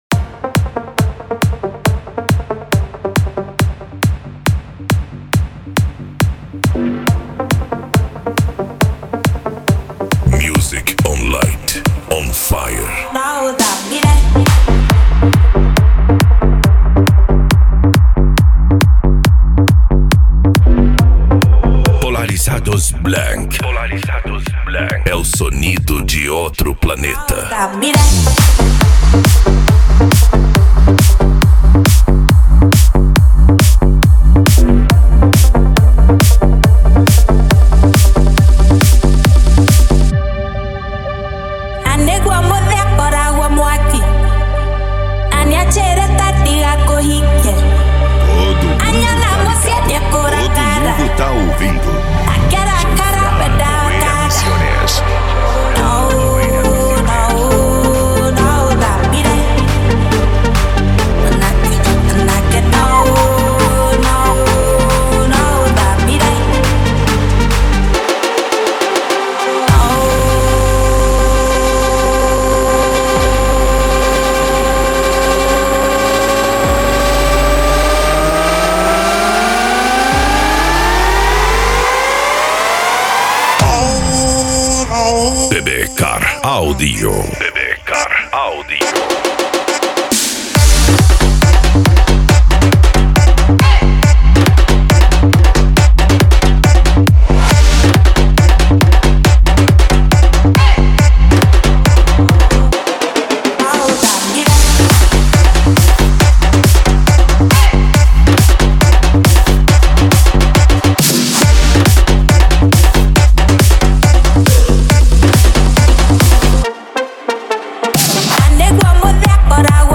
Psy Trance